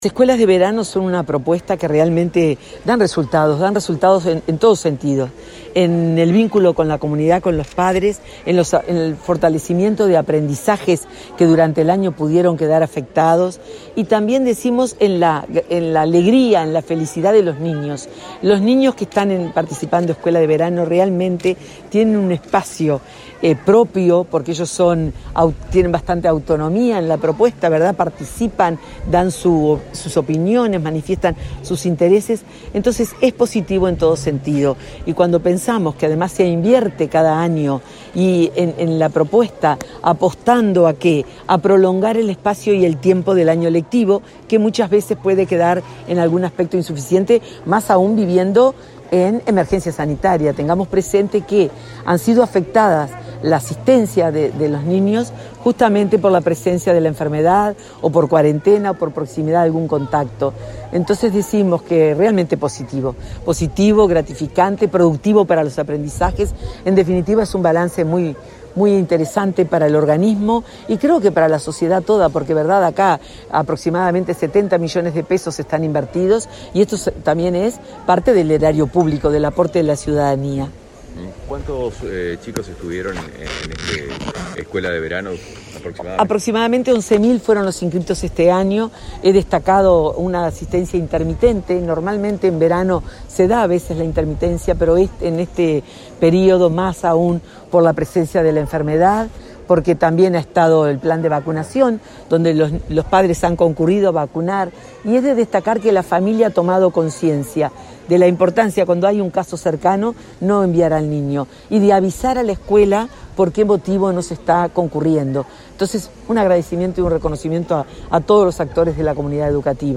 Declaraciones a la prensa de la directora de Primaria
La directora de Primaria, Graciela Fabeyro, participó en el cierre del programa Verano Educativo 2022, en cuatro escuelas de Montevideo y Canelones, y